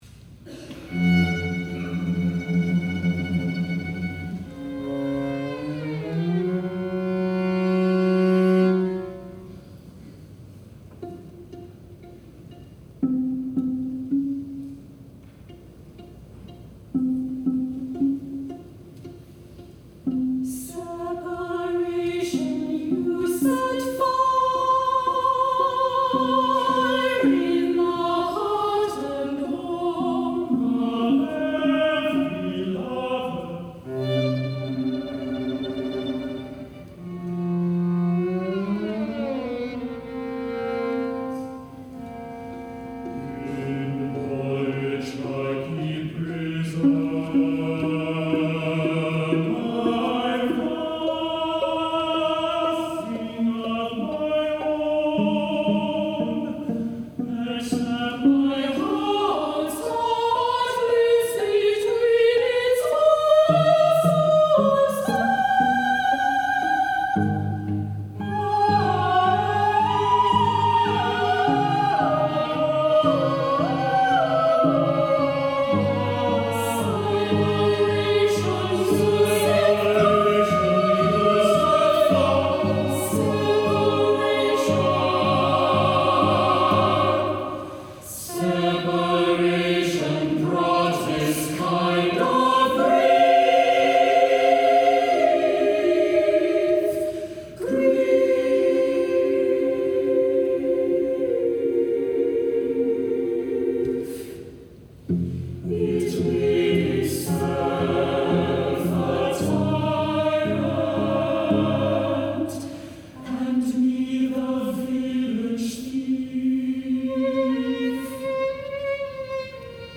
SATB, violin, cello